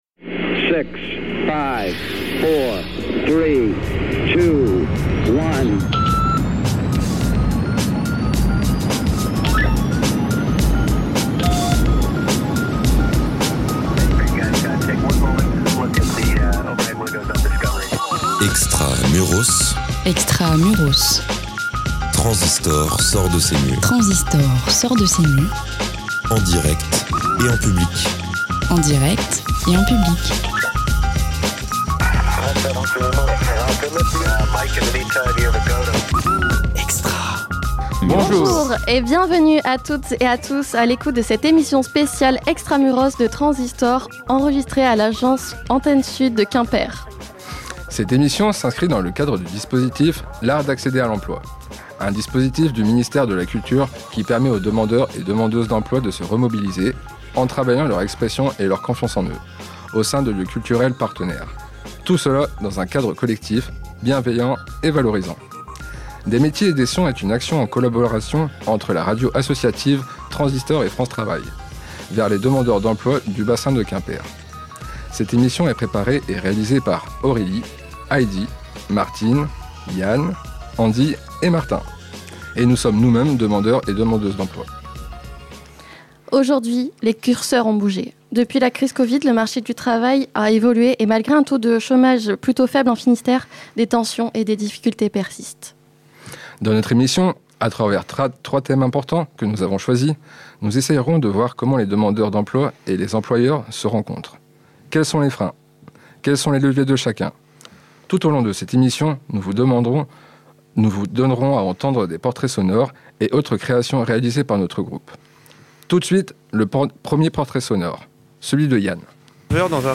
Une émission radio réalisée par des «demandeurs d’emploi » avec France Travail dans le cadre du dispositif L’art d’accéder à l’emploi.
Une émission radiophonique imaginée et animée par les participants, une émission qui parle d'insertion professionnelle, de bien-être au travail, de recrutement et de santé mentale avec deux employeurs, une psychologue du travail France Travail et huit demandeurs d'emploi.